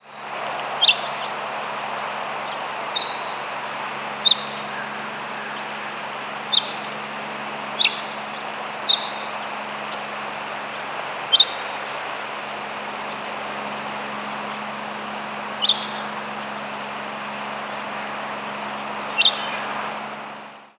House Finch diurnal flight calls
Perched bird giving a variety of call types. Black-capped Chickadee and Tufted Titmouse in the background.